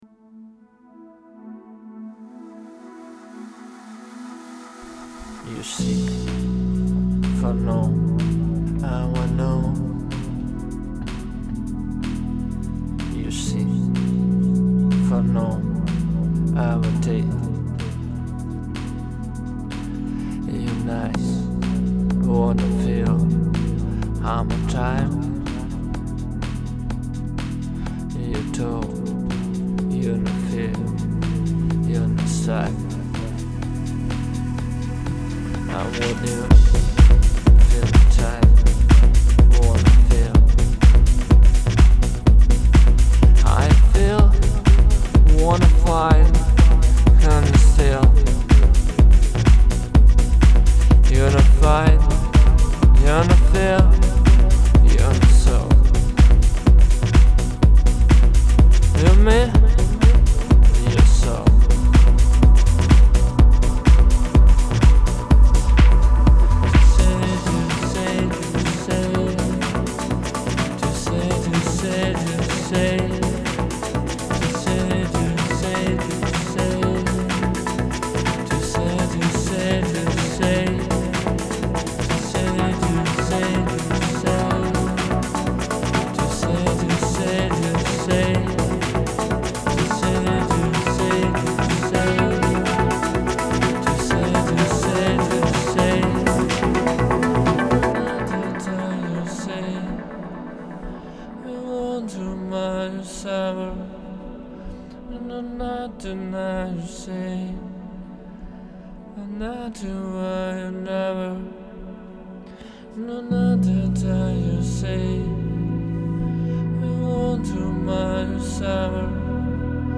Прогрессив Хаус, Прогрессив
Имеется вот такая вот наработка ( на этапе создания) Напел мелодические ходы Также приглашаю всех желающих принять участие!
(меня не устраивает как звучит средний бас)